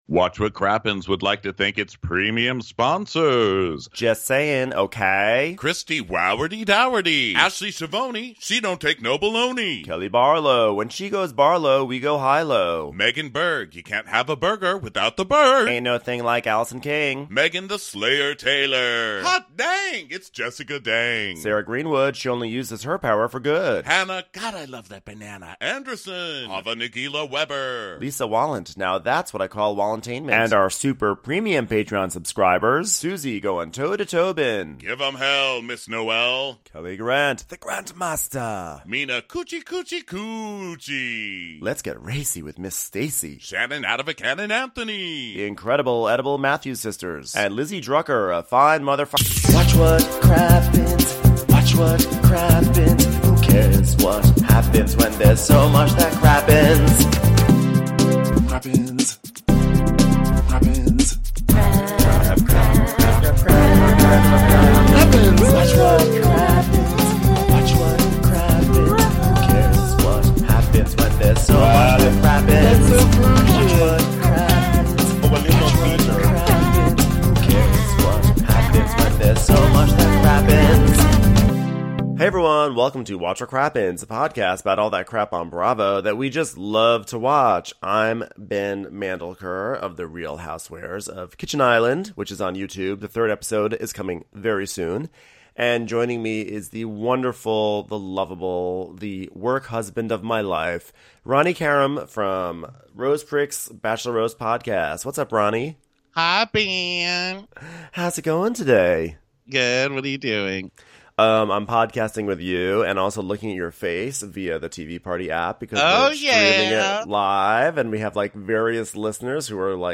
Thanks to everyone who watched us record the episode live on TVPartyApp!